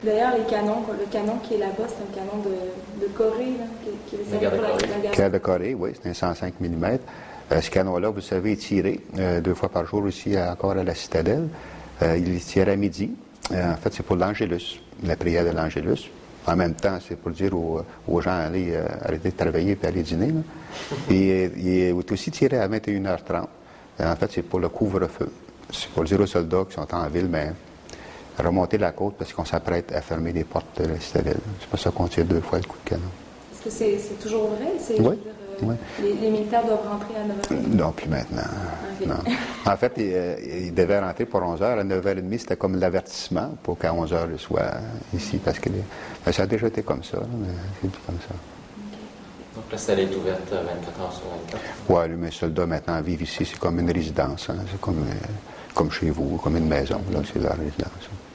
Préparation du coup de canon de midi, © IREPI
945_Extraitcoupdecanon.mp3